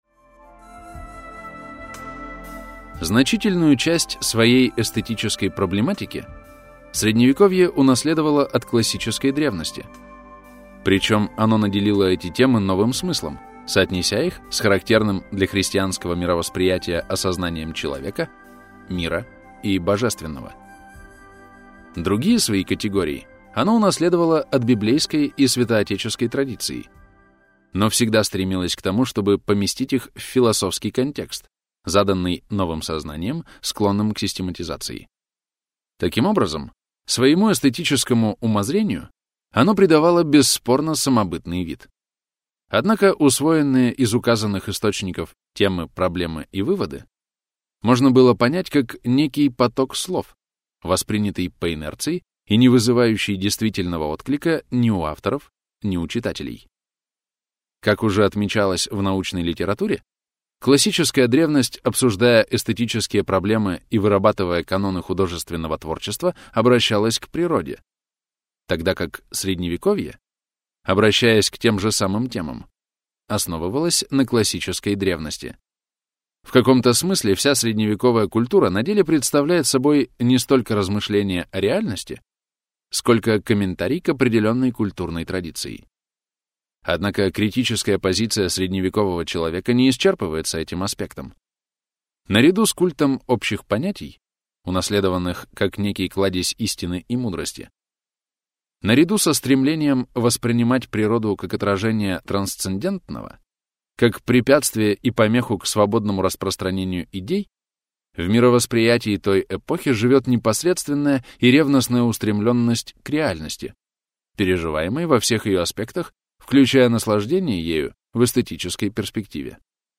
Аудиокнига Искусство и красота в средневековой эстетике | Библиотека аудиокниг